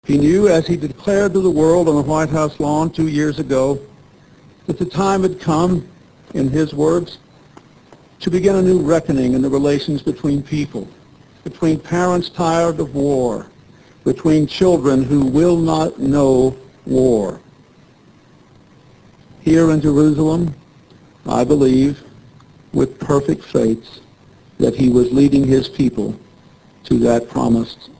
Click on the any of the icons below to download and hear speeches made during the Jerusalem Rabin memorial ceremony of November 6th 1995.
Clinton speech snippet 33k